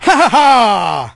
el_primo_kill_03.ogg